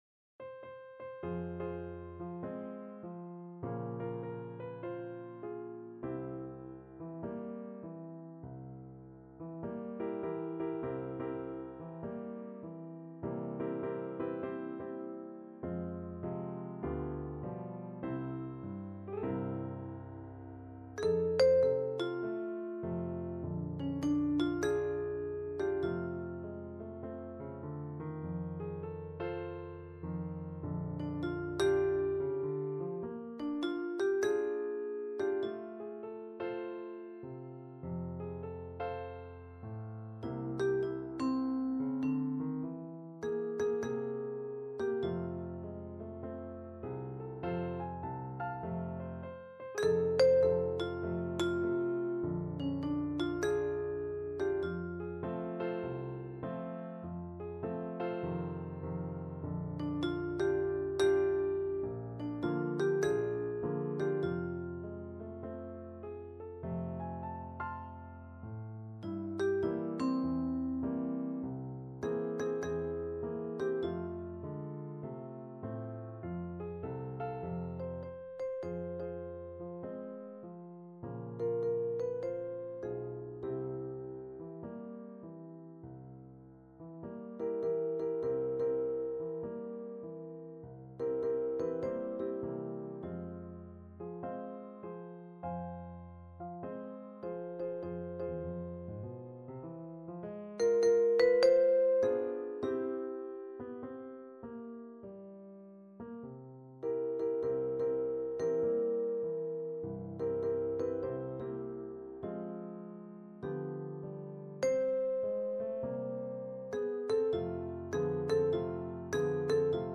Vibraphone
4/4 (View more 4/4 Music)
With a swing = c.100
Jazz (View more Jazz Percussion Music)